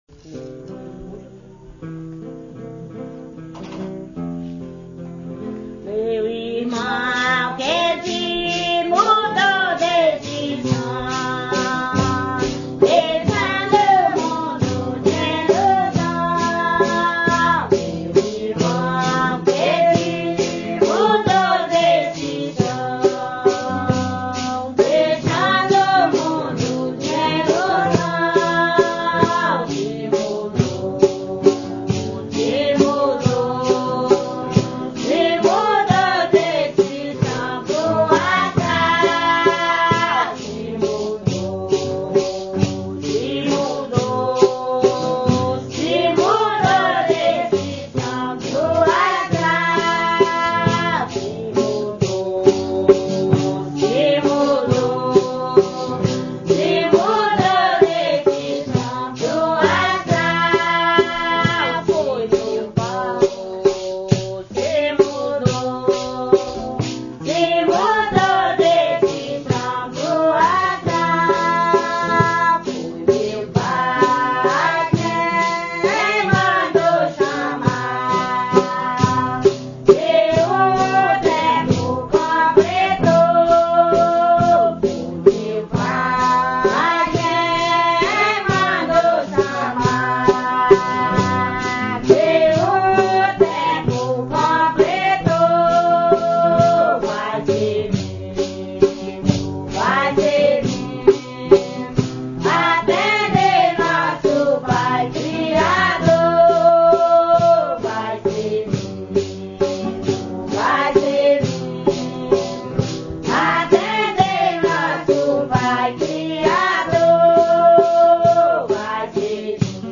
valsa